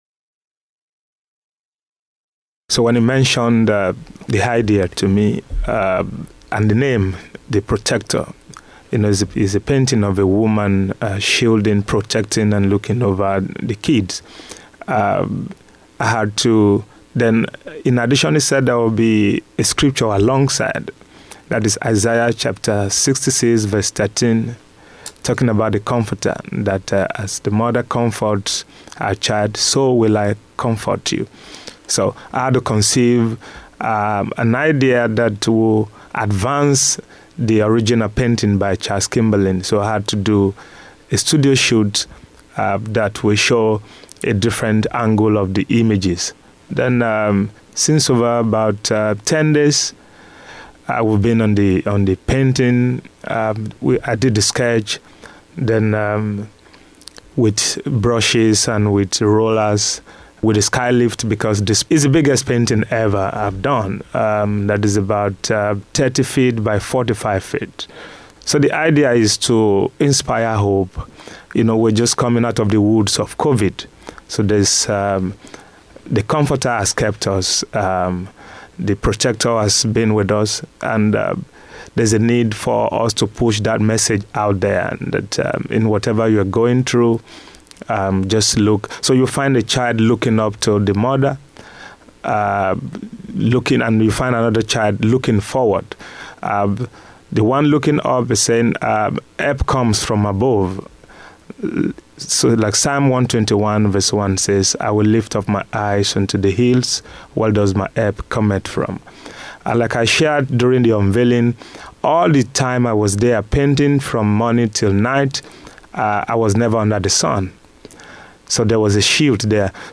We spoke to both individuals about the mural, and further projects they may have down the line.